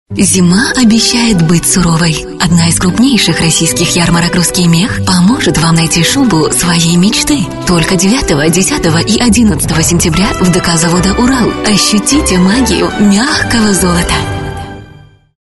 Native speaker Female 30-50 lat
Soft, gentle voice timbre, versatile applications.
Nagranie lektorskie